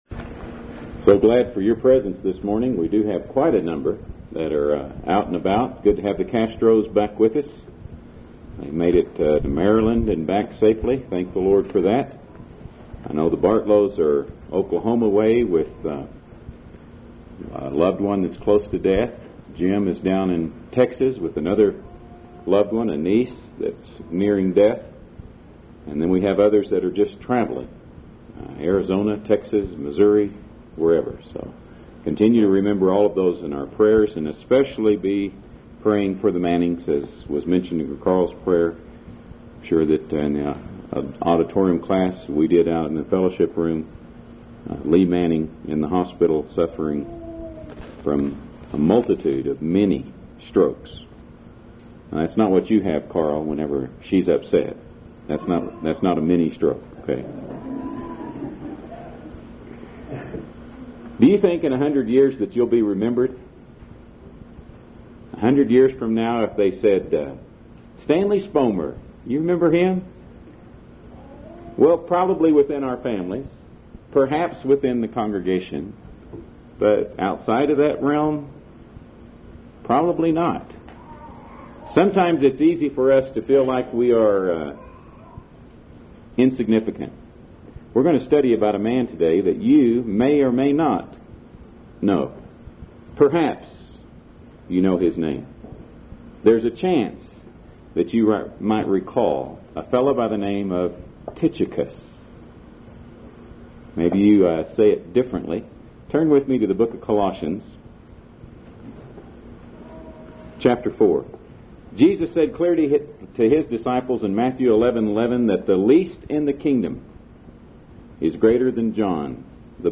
← Newer Sermon Older Sermon →